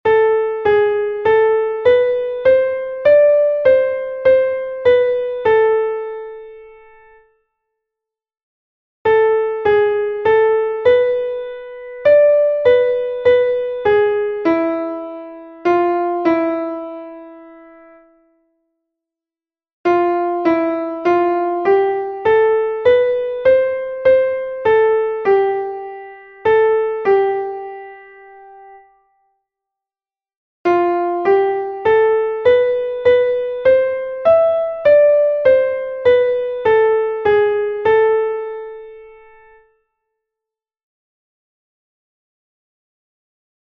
It is a compound triple meter.
leccion1_ritmo_9_8.mp3